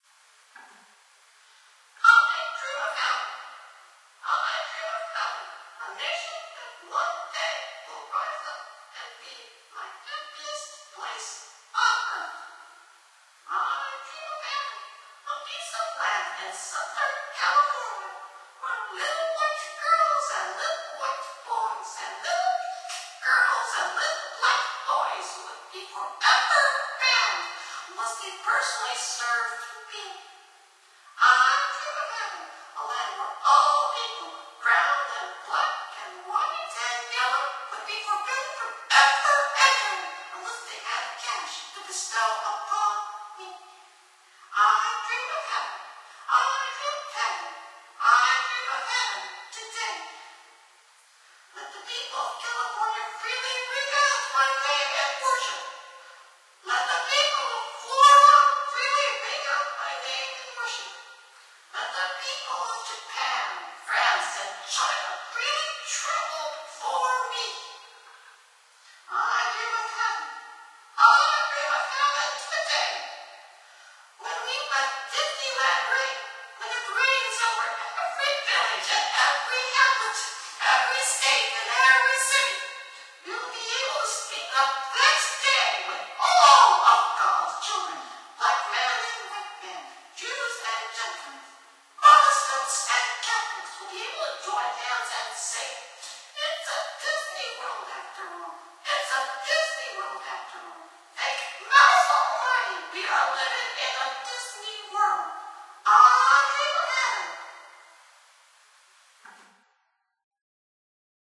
A recording of part of Mickey Mouse the Great’s “I Dream of Having” speech on July 6, 1954.